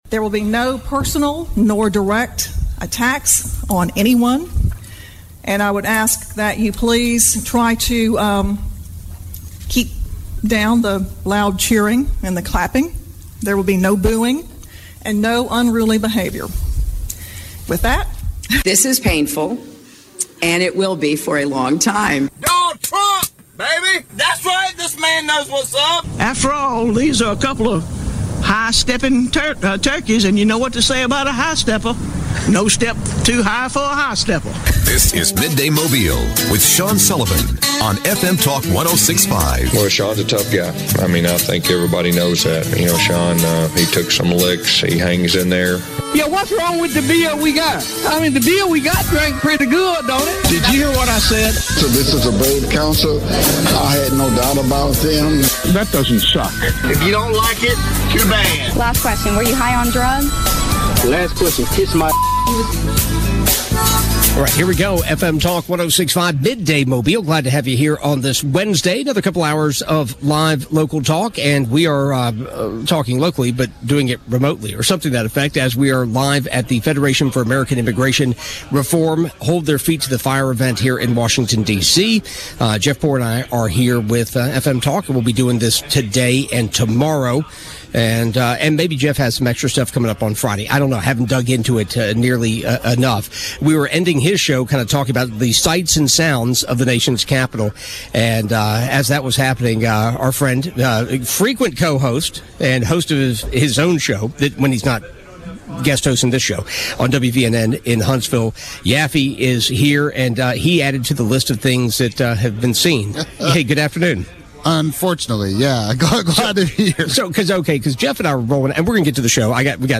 Midday Mobile - Live from Washington DC - September 21 2022